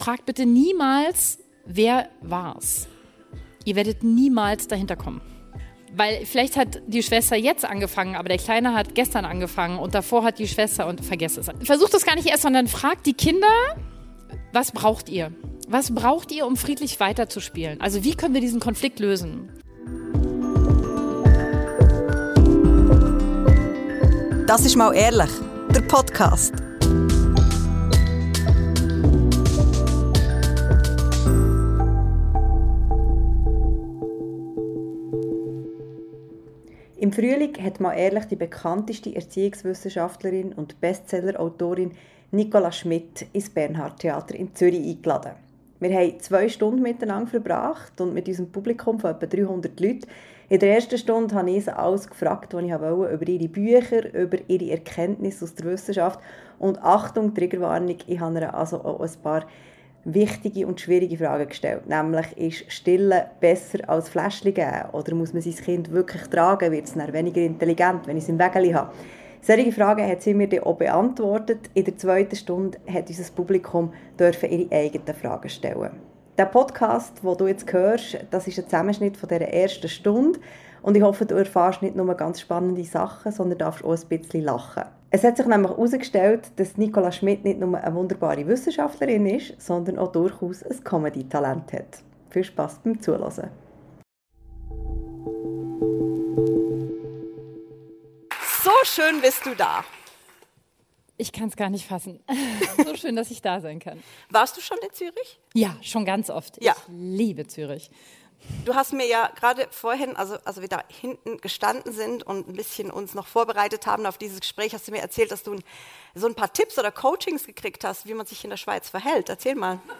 Gespräche rund um Elternsein, persönliche Bedürfnisse und Gesellschaftsthemen.